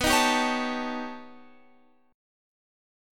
B Augmented 9th